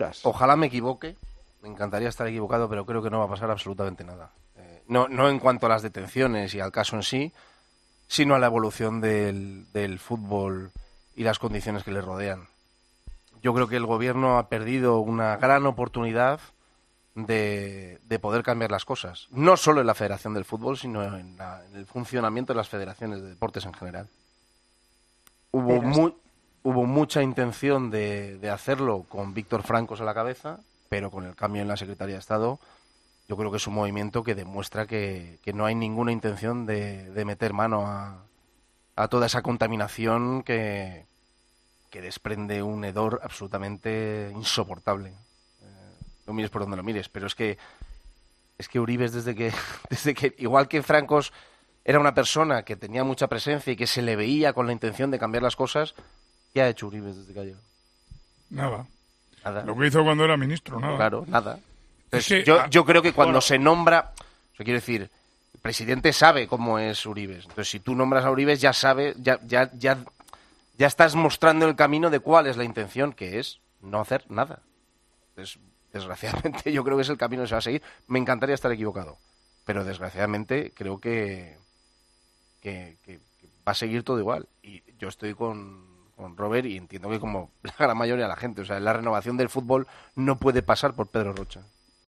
El comentarista de El Partidazo de COPE fue muy crítico con la actitud del Gobierno con toda la polémica que rodea a Luis Rubiales y a la Federación Española.